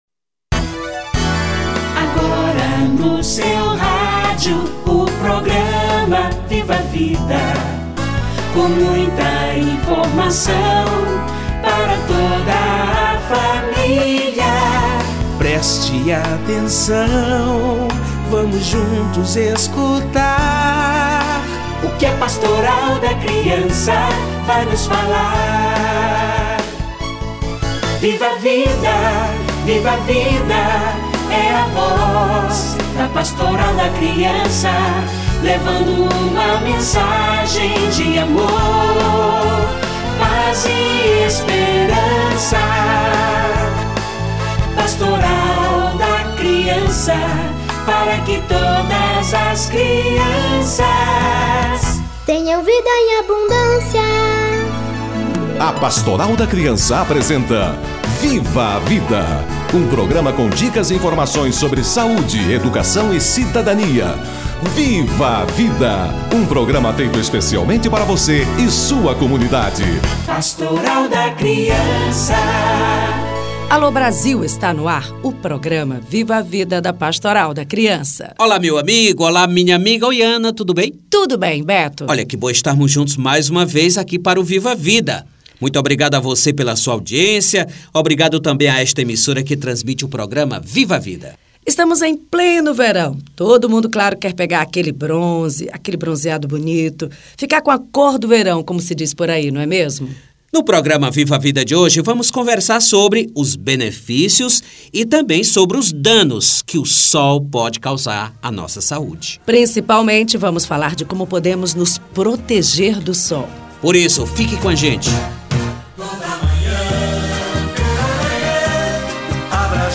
Criança e o Sol - Entrevista